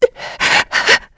Isolet-Vox_Sad1_kr.wav